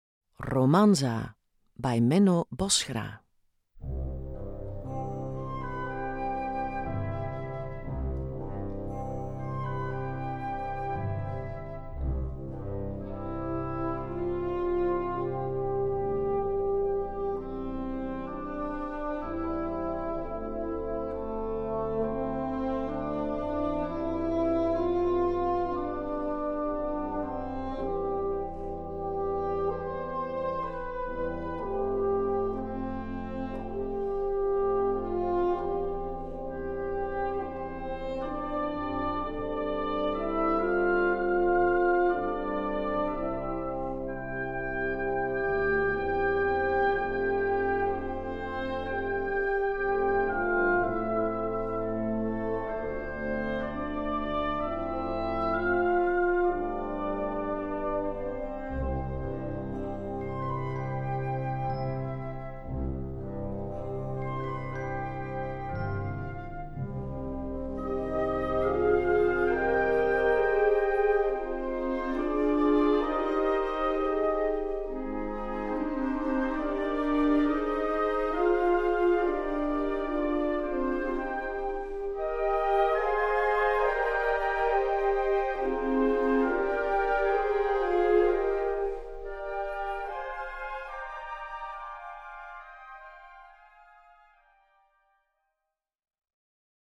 Gattung: Ballade
Besetzung: Blasorchester